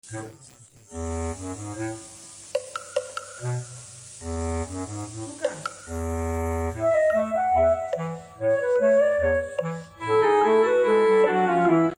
ব্যাকগ্রাউন্ড সঙ্গীত